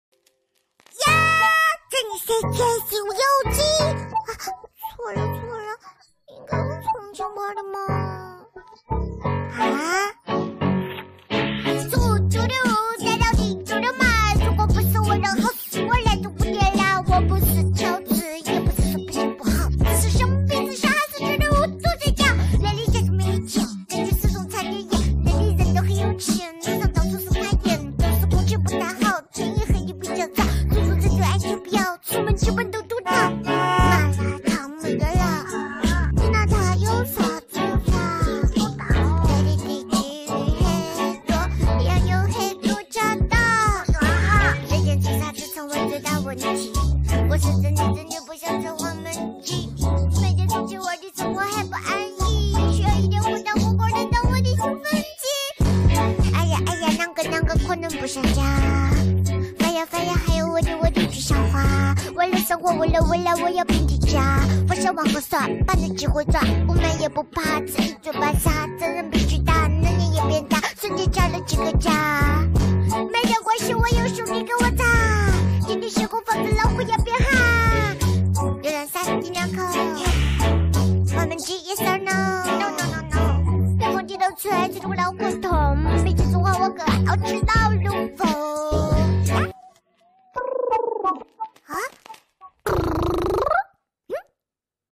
超high的奶味rap.mp3